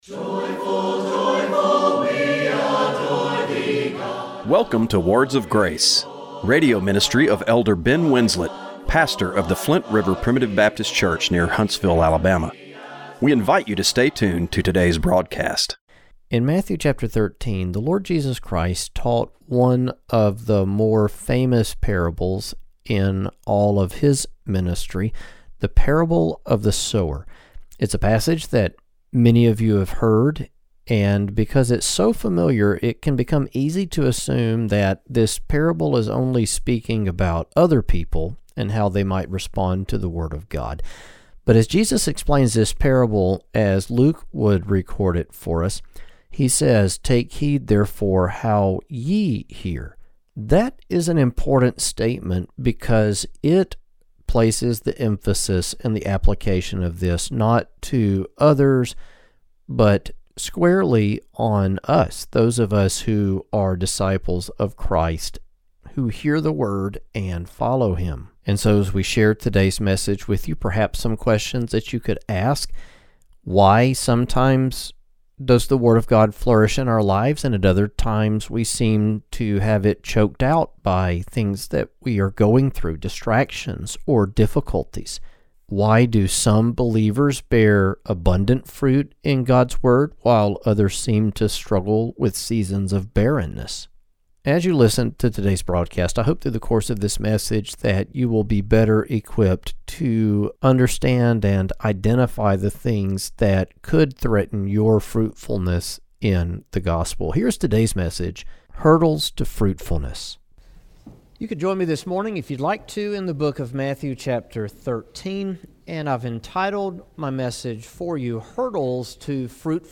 Radio broadcast for April 12, 2026.